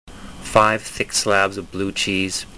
使用した音声はthe speech accent archiveenglish1です。
文"five thick slabs of blue cheese"の単語"five"の"i"でした。